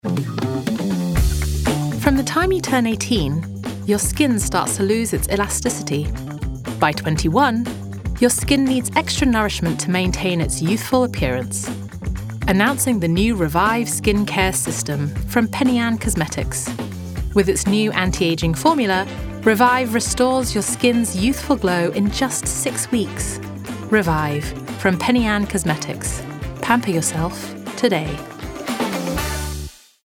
Voice Sample: Bodycare
We use Neumann microphones, Apogee preamps and ProTools HD digital audio workstations for a warm, clean signal path.